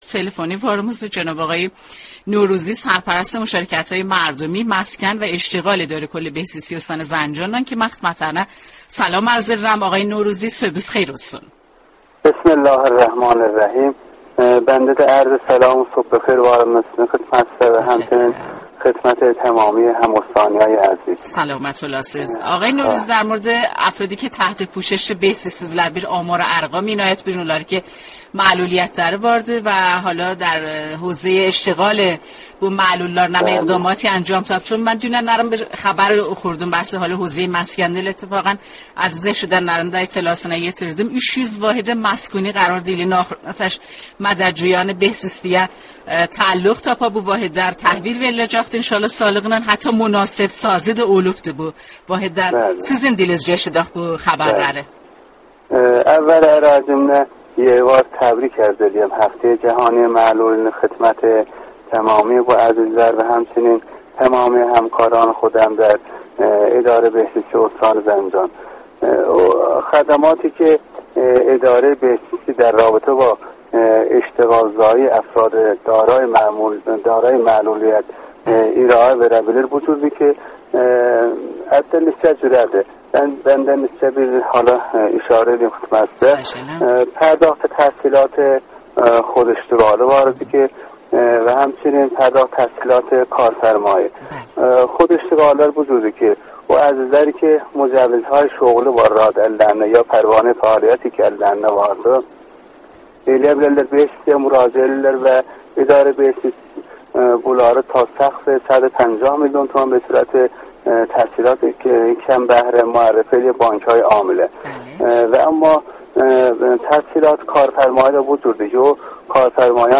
دریافت 2 MB کد خبر 124085 برچسب‌ها هفته افراد دارای معلولیت مصاحبه رادیویی